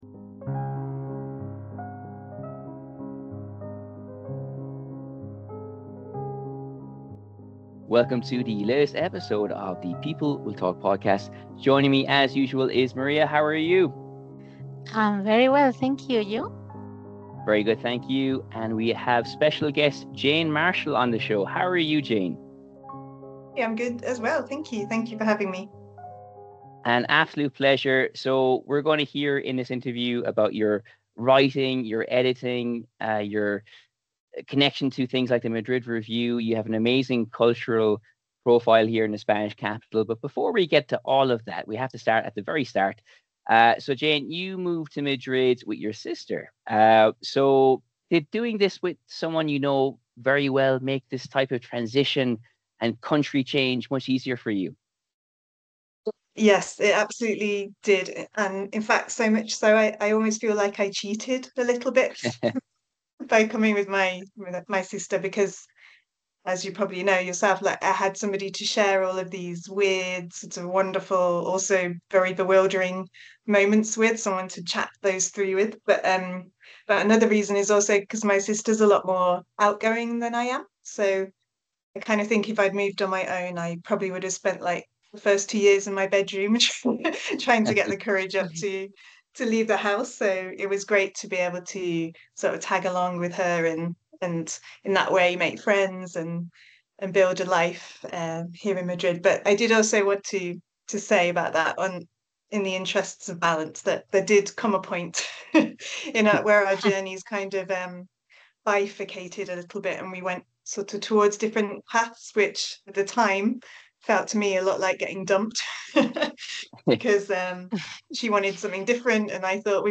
Quite simply, she has a lot of strings to her bow, and she joined us for a fascinating chat.